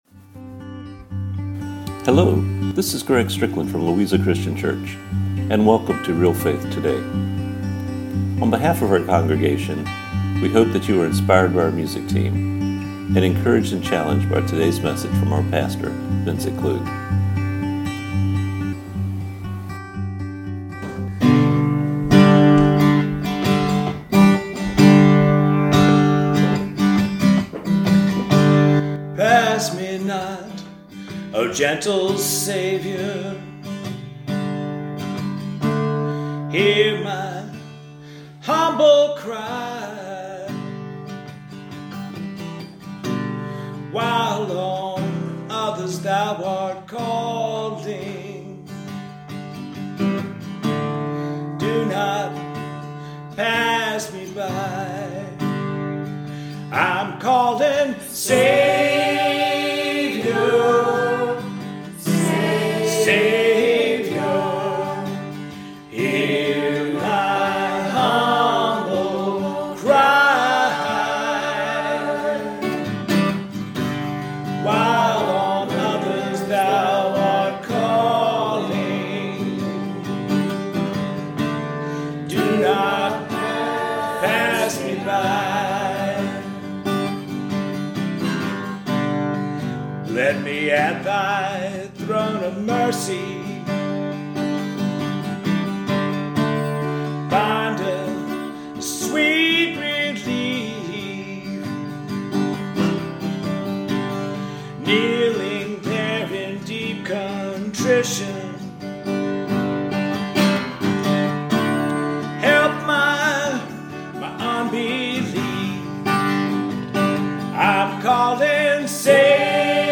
Radio Program
James-part-3-Radio-Broadcast.mp3